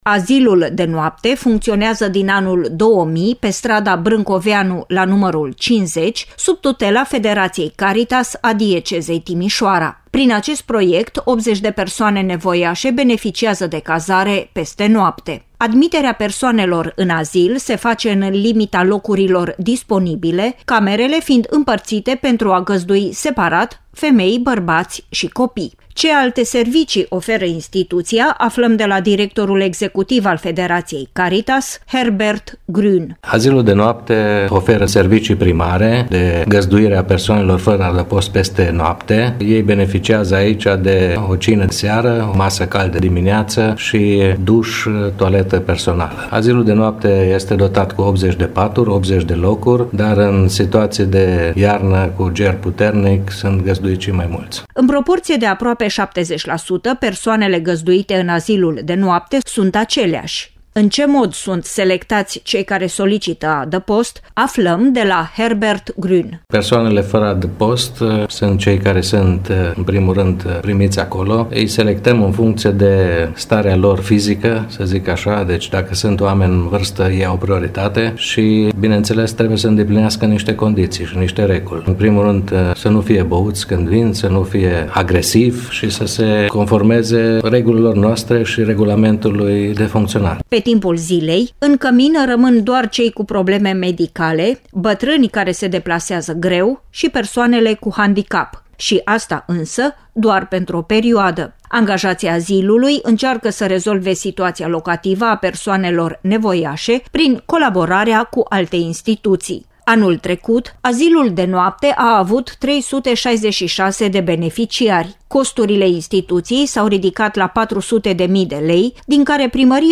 UPDATE sinteza emisiunii